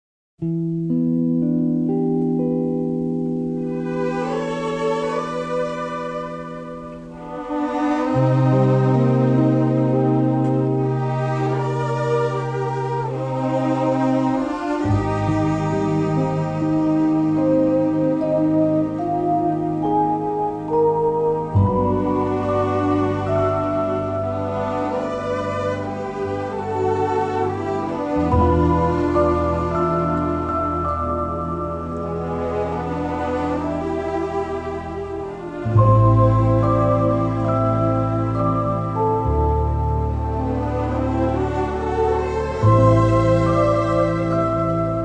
jazz and mood cues
shimmering with color, charm and melody